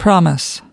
/ˈprɑː.mɪs/